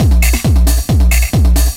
DS 135-BPM D3.wav